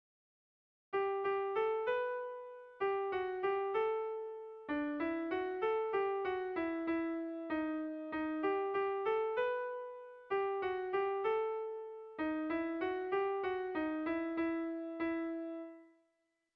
Erlijiozkoa
Lauko ertaina (hg) / Bi puntuko ertaina (ip)
A1A2